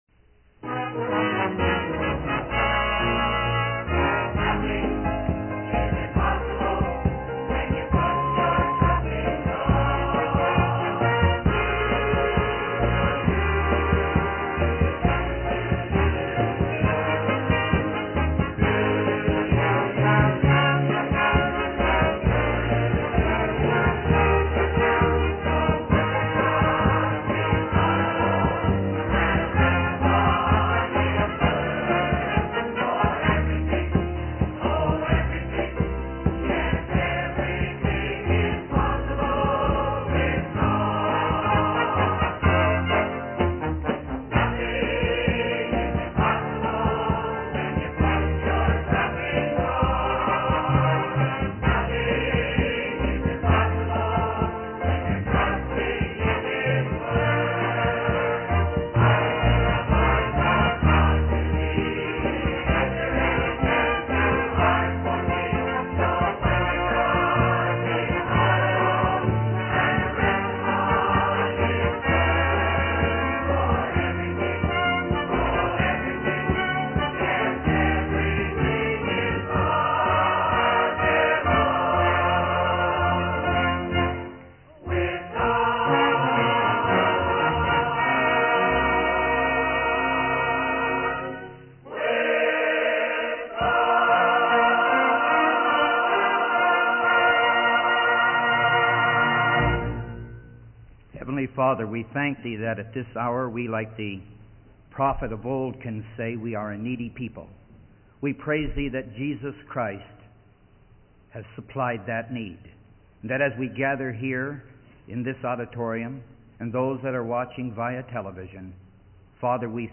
He then appeals to the television audience for support in keeping the telecast going, highlighting the importance of the program for those who depend on it for their spiritual life.